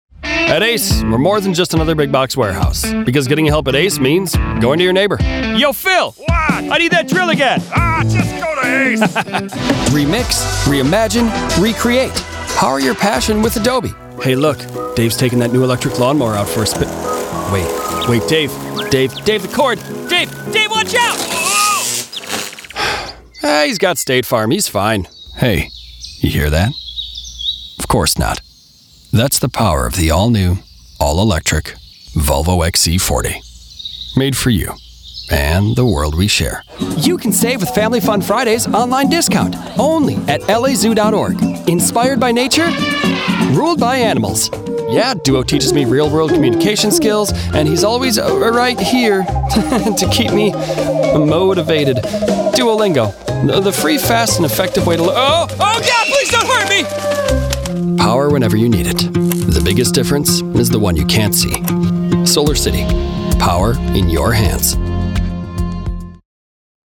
Englisch (Amerikanisch)
Natürlich, Unverwechselbar, Cool, Freundlich, Warm
Erklärvideo
Kommerziell